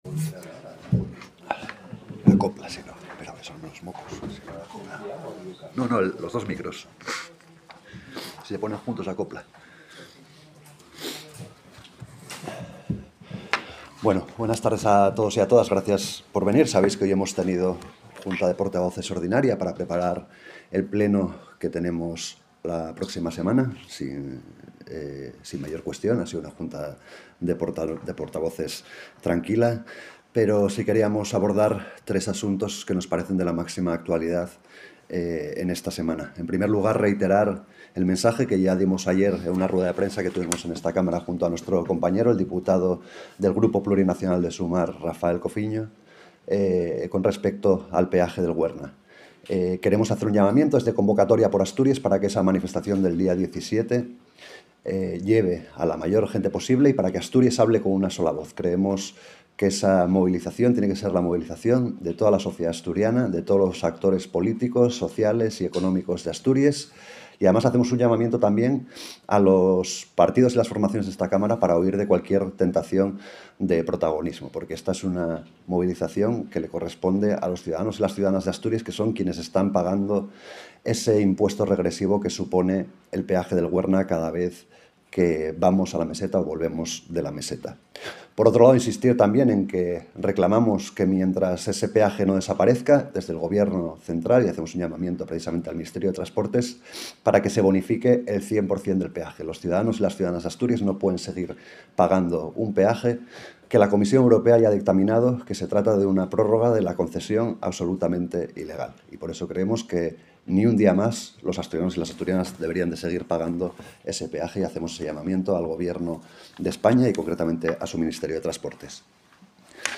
El voceru de Izquierda Xunida IX-Convocatoria por Asturies, Xabel Vegues, compareció esta mañana na sala de prensa tres la Xunta de Voceres pa tratar trés asuntos de máxima actualidá.